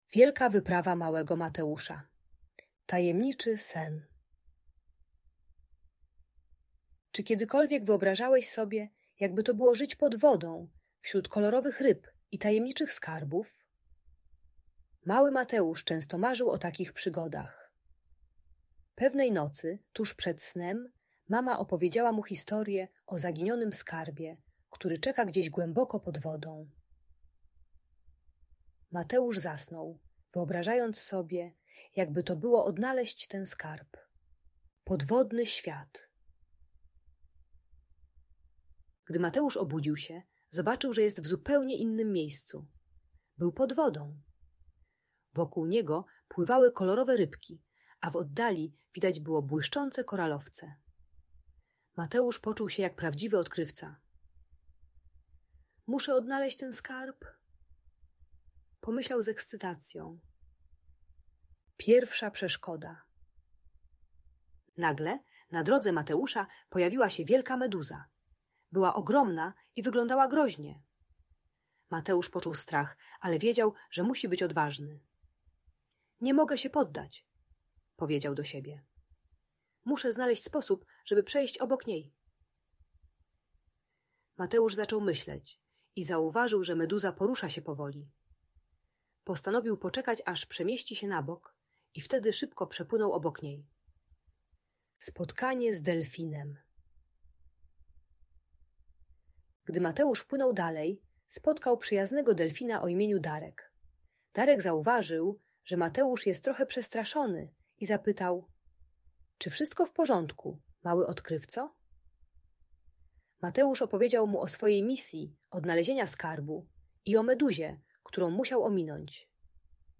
Wielka Wyprawa Małego Mateusza - Audiobajka dla dzieci